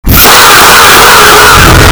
Fahh Loud Asf Sound Button - Free Download & Play
The Fahh Loud Asf sound button is a popular audio clip perfect for your soundboard, content creation, and entertainment.